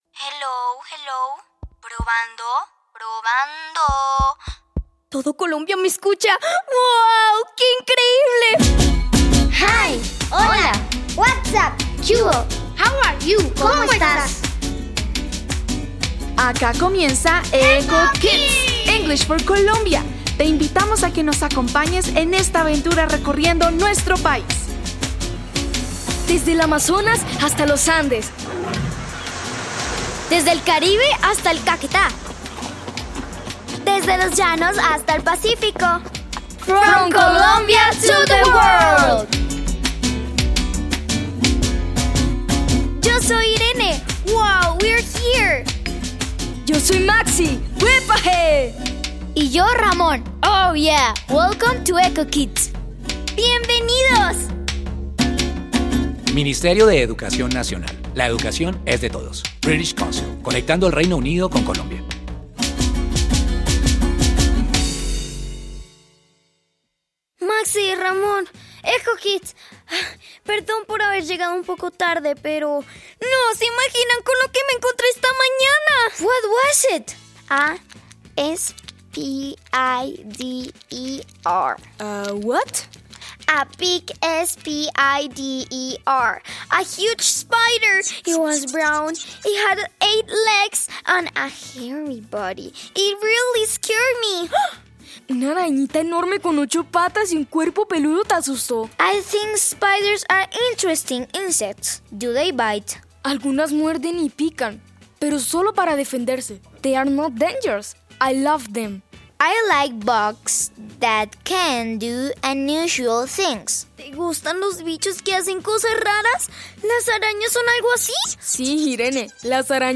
Introducción Este recurso ofrece un episodio radial de Eco Kids sobre temas de diversidad en una clase de biología. Presenta diálogos sencillos en inglés y situaciones que fortalecen la comprensión del idioma.